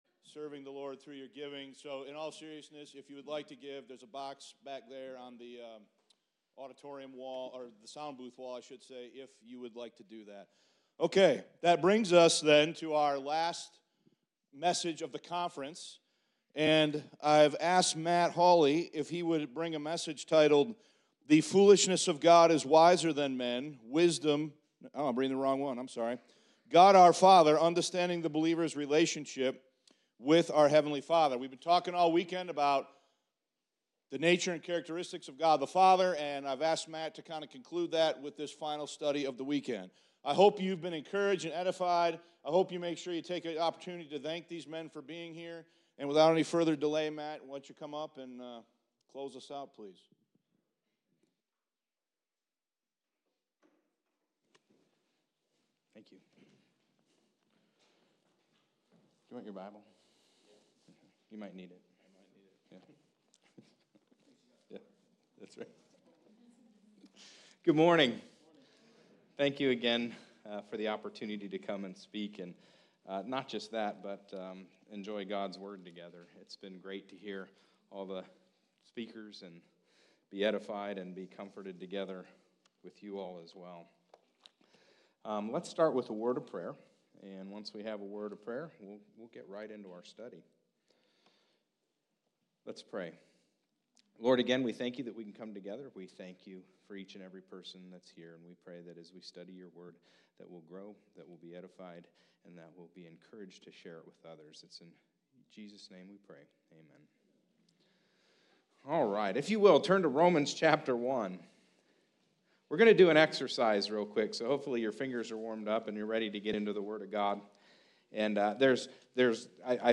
2024 West Michigan Grace Bible Conference